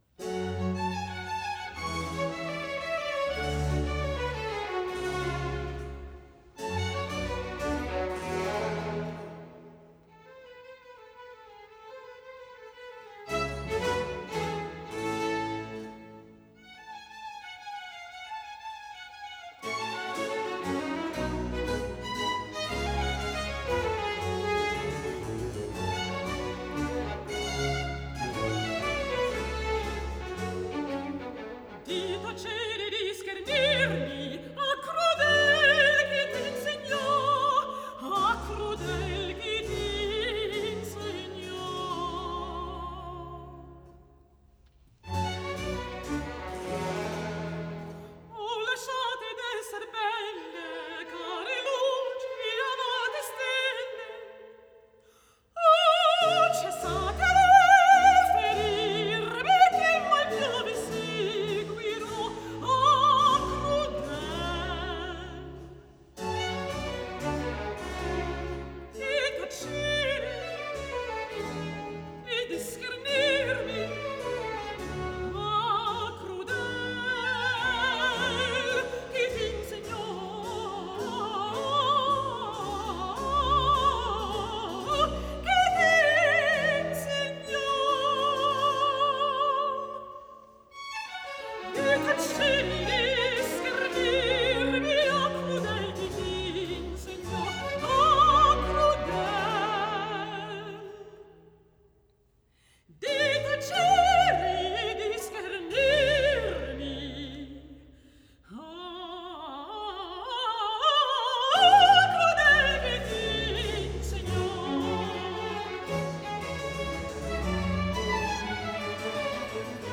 Act 1_ Aria_ _Aspide sono